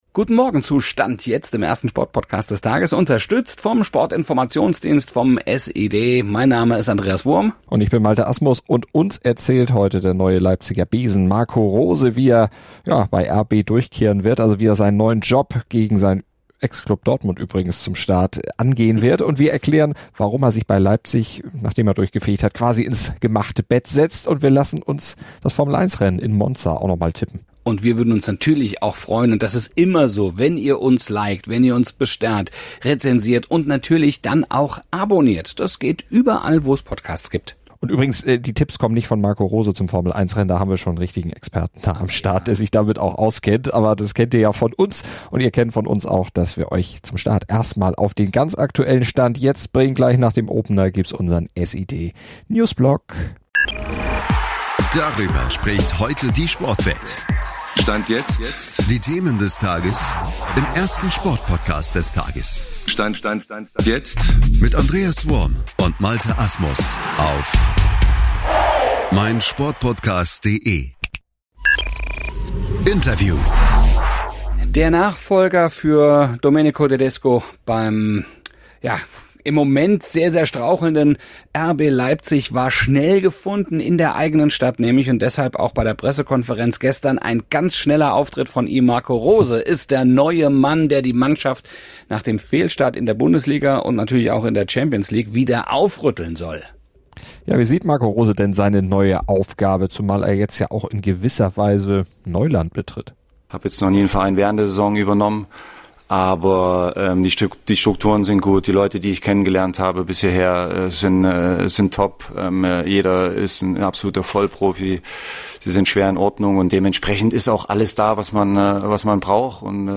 Bei Stand jetzt hört ihr von Marco Rose, wie er sich die Aufgabe RB Leipzig vorstellt und sie angeht, was er von Max Eberl hält.